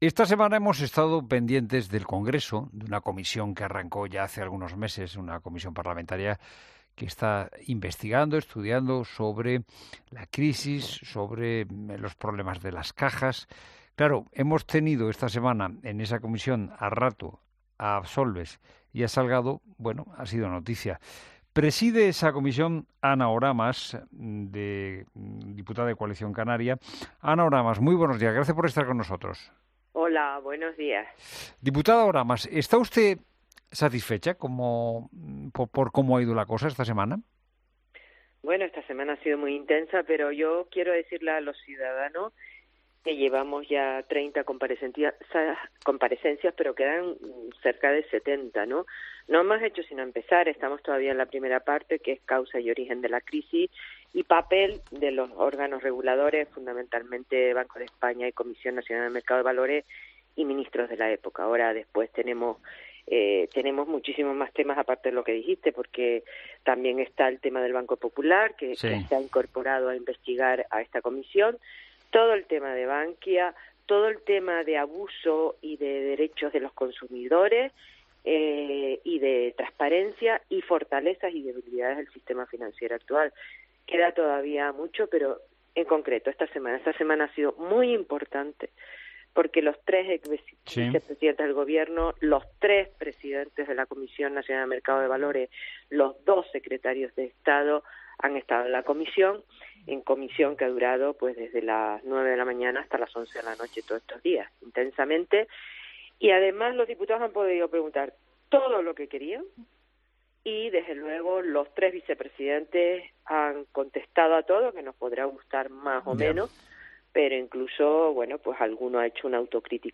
Entrevista política